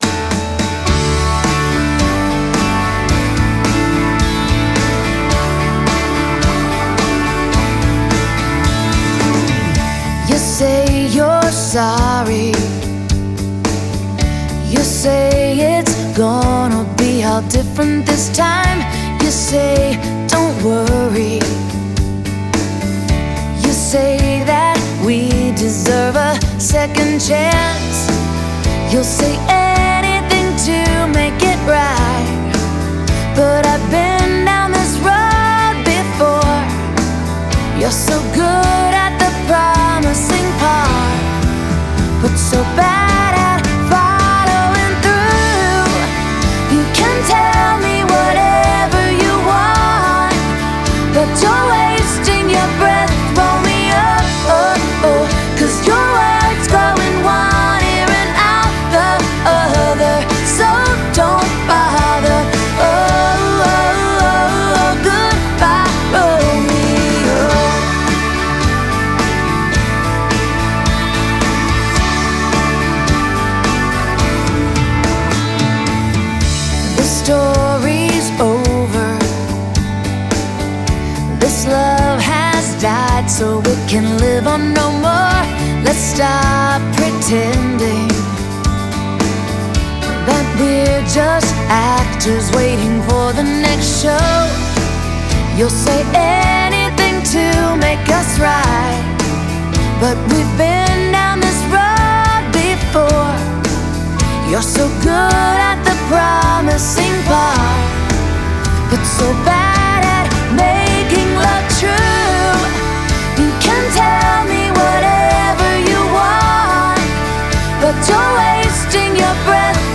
Drums
Bass
Electric Guitar
Keys